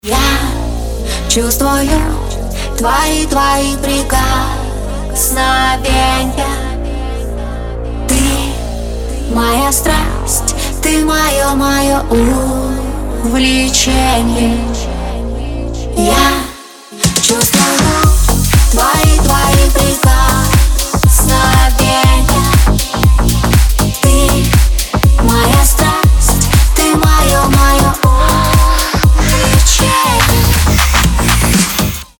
поп
громкие
женский вокал
dance